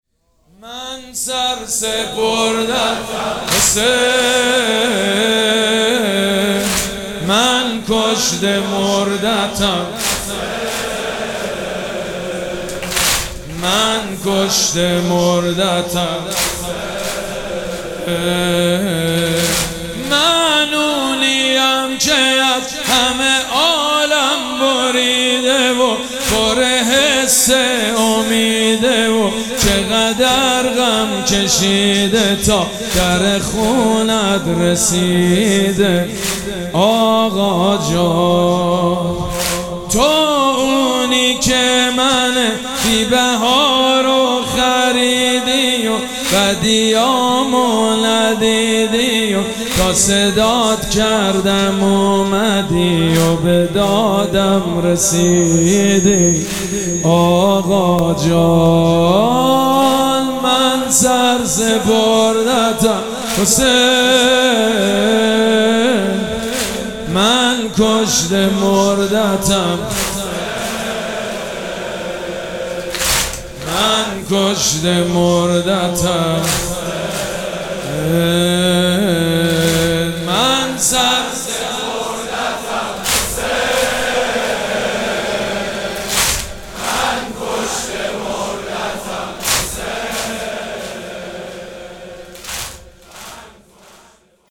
مراسم عزاداری شب هشتم محرم الحرام ۱۴۴۷
مداح
حاج سید مجید بنی فاطمه